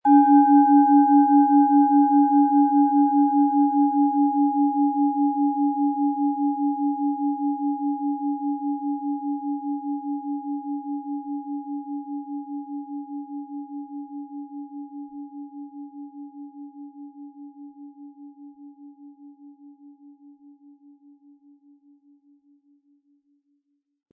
Planetenschale®
• Mittlerer Ton: Tageston
Den passenden Klöppel erhalten Sie umsonst mitgeliefert, er lässt die Schale voll und wohltuend klingen.
PlanetentöneMerkur & Tageston
MaterialBronze